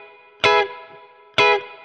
DD_StratChop_130-Emaj.wav